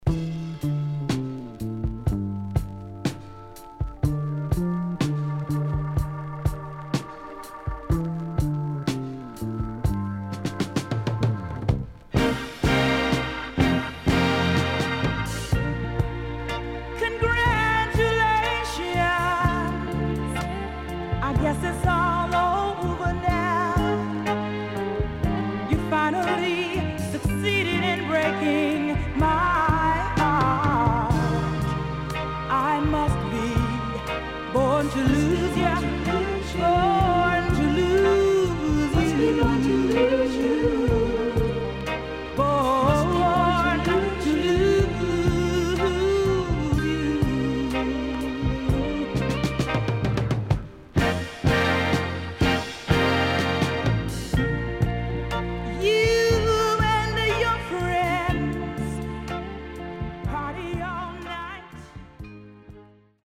HOME > SOUL / OTHERS
SIDE A:少しノイズ入りますが良好です。